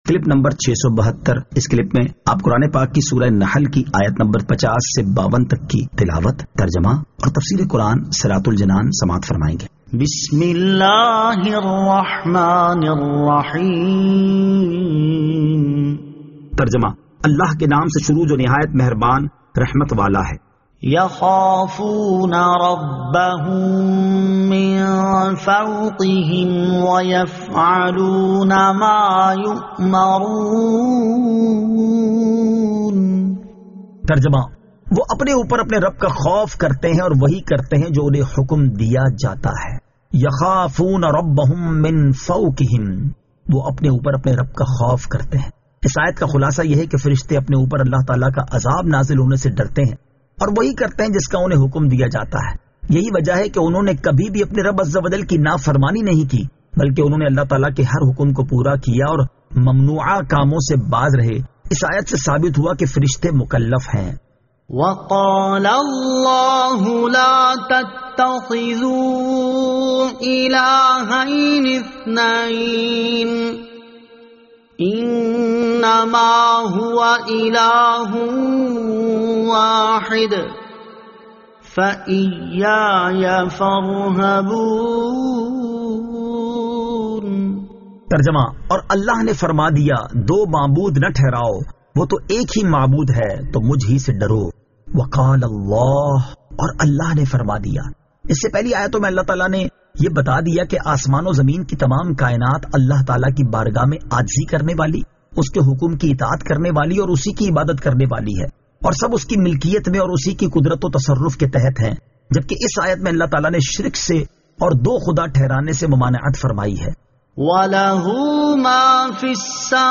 Surah An-Nahl Ayat 50 To 52 Tilawat , Tarjama , Tafseer